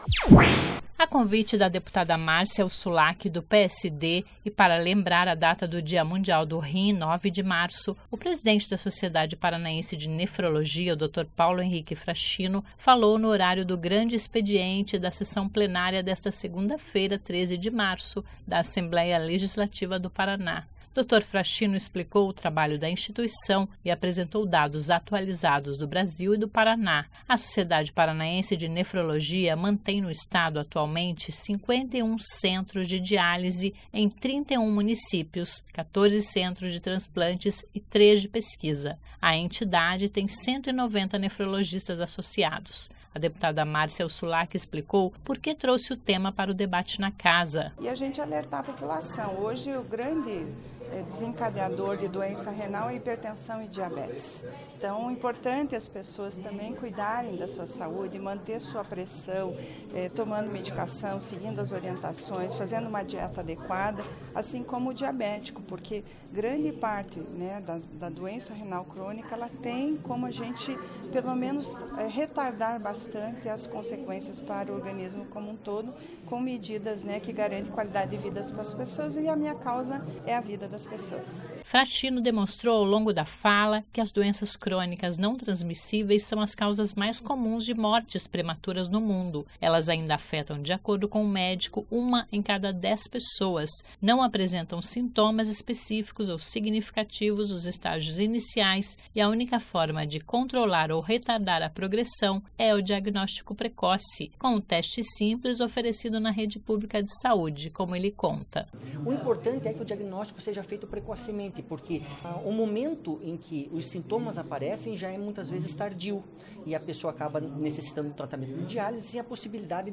A deputada Marcia Huçulak explicou porque trouxe o tema para o debate na Casa.
(Sonora)